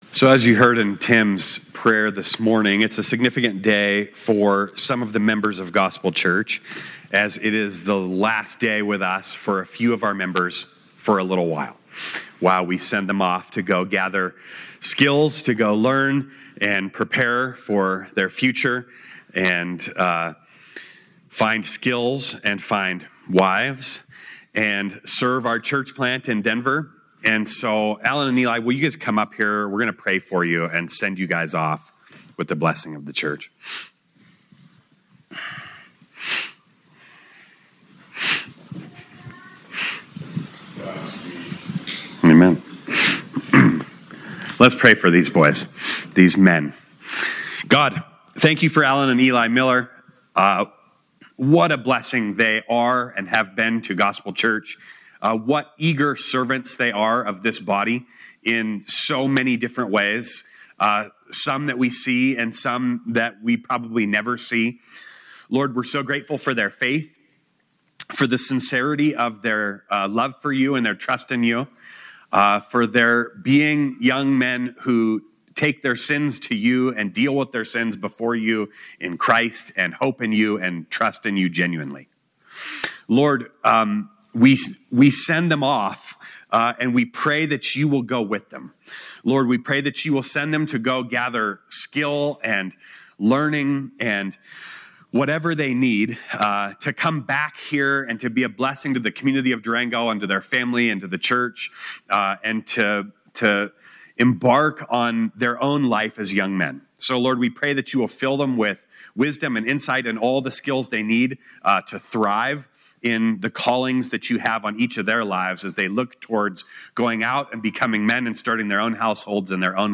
Location: Gospel Church Durango